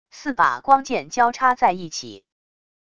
四把光剑交叉在一起wav音频